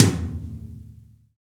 Index of /90_sSampleCDs/Roland L-CD701/TOM_Real Toms 1/TOM_Ac.Toms 1
TOM AMBGRT0B.wav